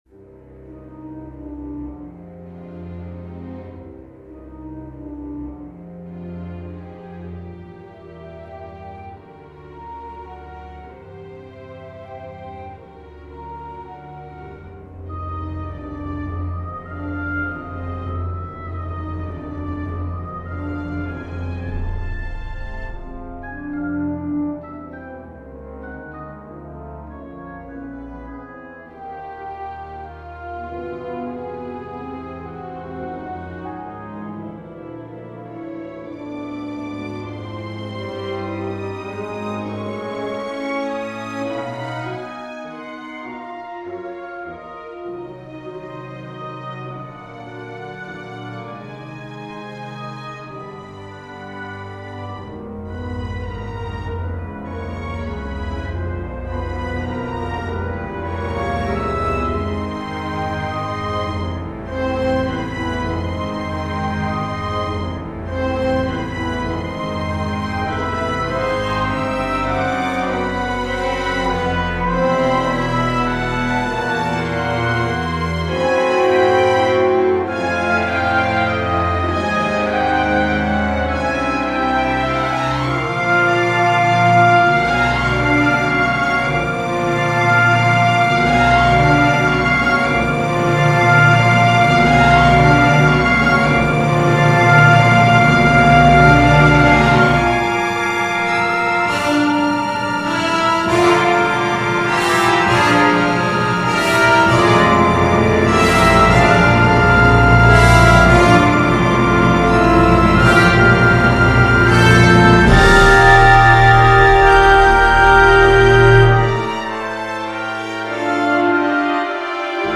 Exercise in Heavily Modulatory Music - Orchestral and Large Ensemble - Young Composers Music Forum
Lately I've been upset about the lack of modulations in my music, and so I decided to go to the edge of wh...